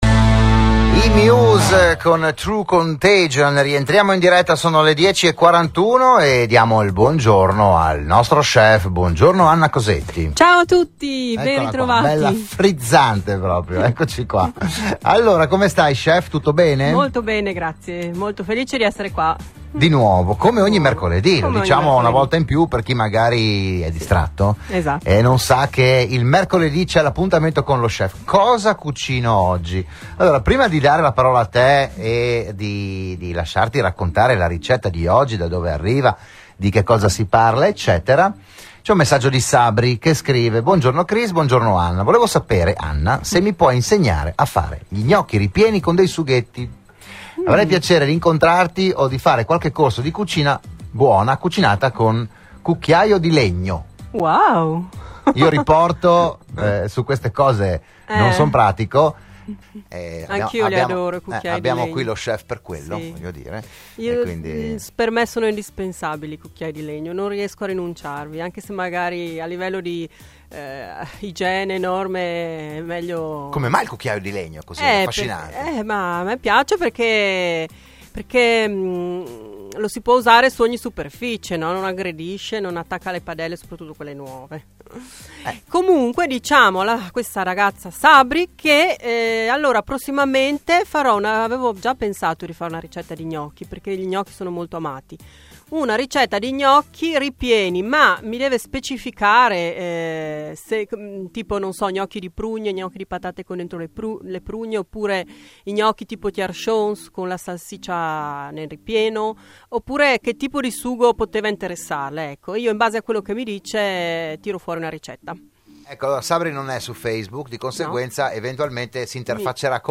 la rubrica all’interno di “RadioAttiva“, la trasmissione di Radio Studio Nord